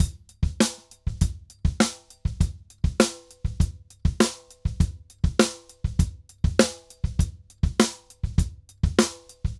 Drums_Samba 100_1.wav